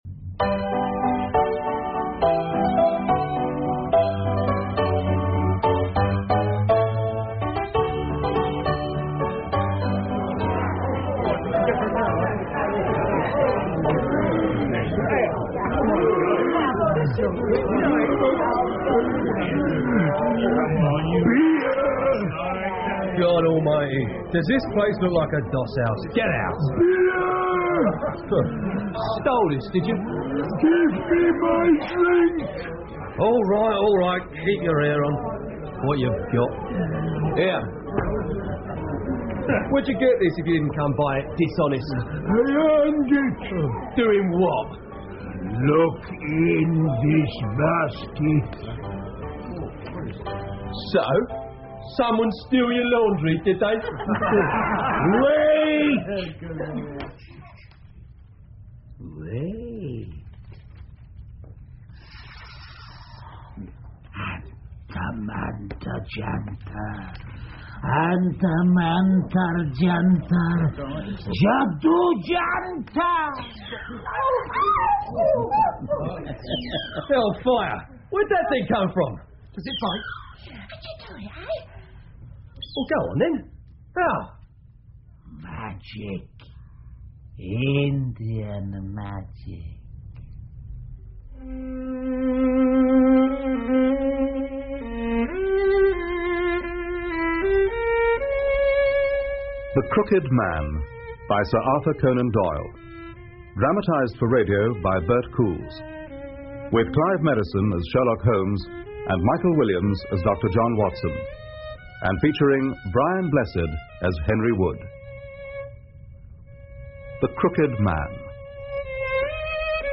福尔摩斯广播剧 The Crooked Man 1 听力文件下载—在线英语听力室